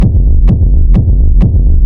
• Techno Sub Kick Black Industrial.wav
Techno_Sub_Kick_Black_Industrial_4__pRH.wav